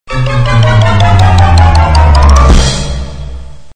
紧张音效铃声二维码下载